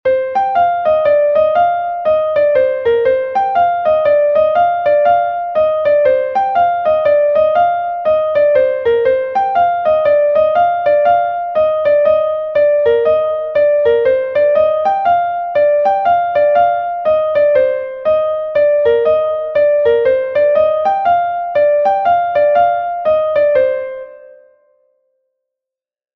un Laridé de Bretagne